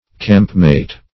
campmate - definition of campmate - synonyms, pronunciation, spelling from Free Dictionary
campmate \camp"mate`\ n.